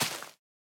Minecraft Version Minecraft Version snapshot Latest Release | Latest Snapshot snapshot / assets / minecraft / sounds / block / big_dripleaf / tilt_down3.ogg Compare With Compare With Latest Release | Latest Snapshot
tilt_down3.ogg